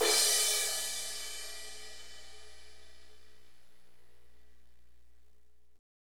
Index of /90_sSampleCDs/Northstar - Drumscapes Roland/DRM_Hip-Hop_Rap/CYM_H_H Cymbalsx